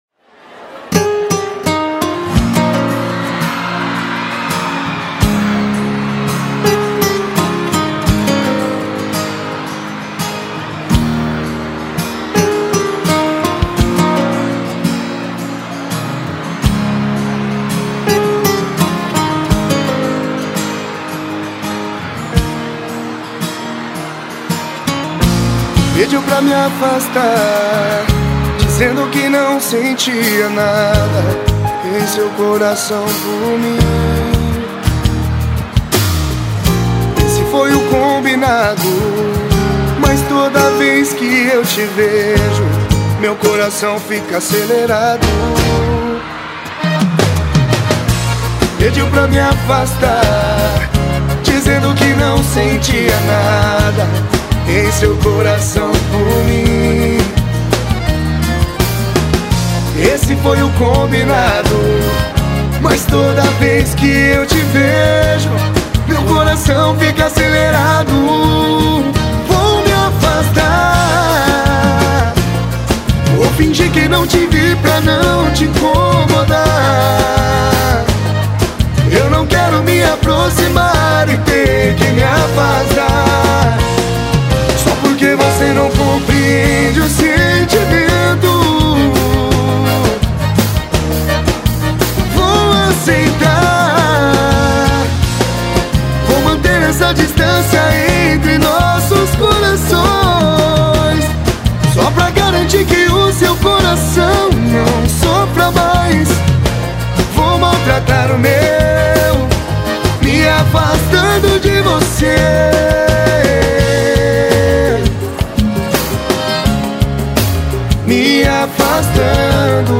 EstiloSertanejo